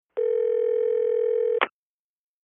Futz|Touch Tone Rings | Sneak On The Lot